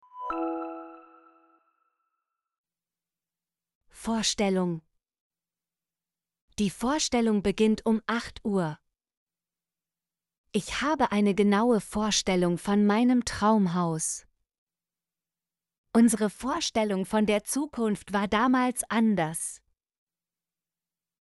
vorstellung - Example Sentences & Pronunciation, German Frequency List